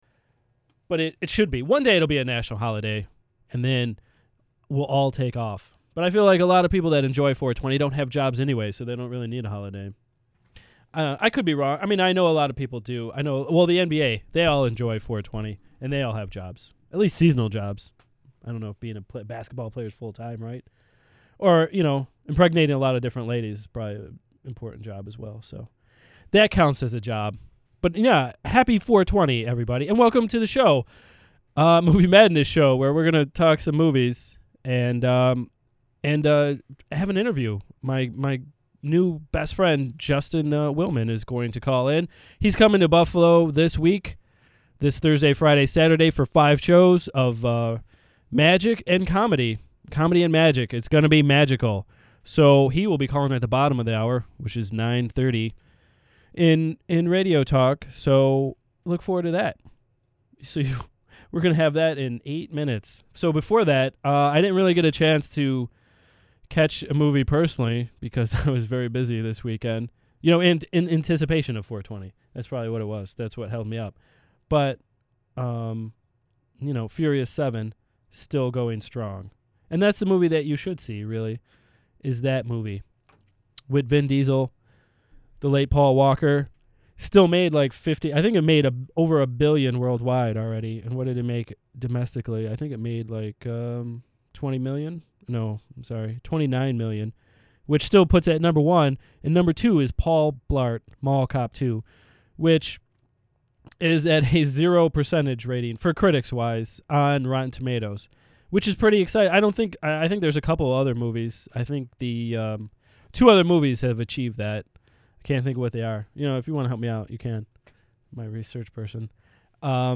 Episode 123: A chat with comic Justin Willman and movie chatter
comedy interview